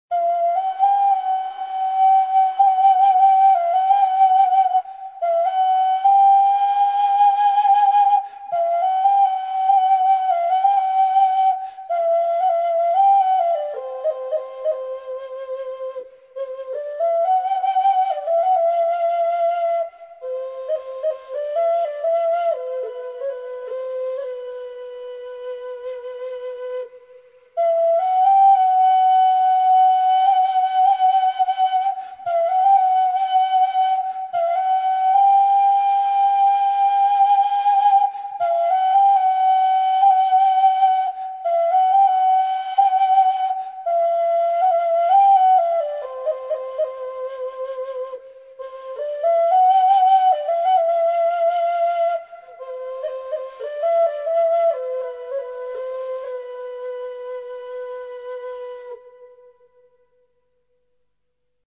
Переложение для саз сырная.
Саз-сырнай